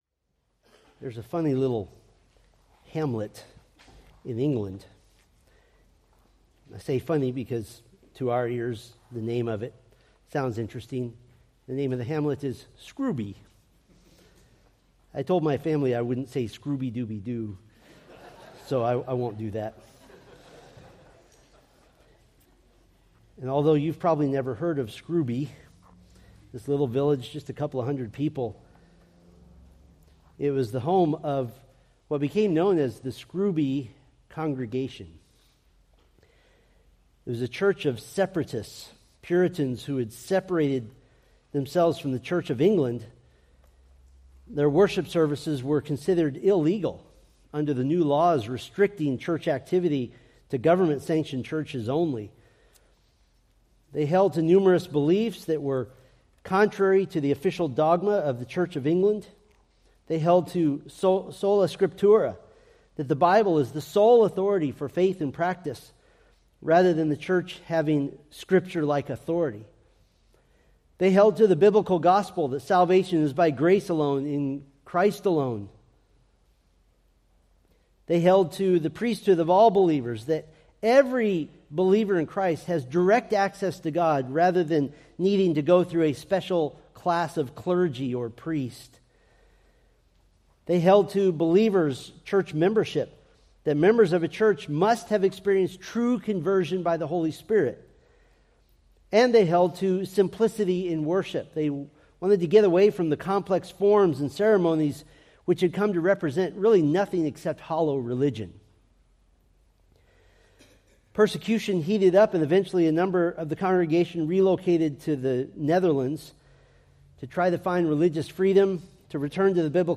Sermon audio from the preaching ministry of Grace Bible Church of Bakersfield, California.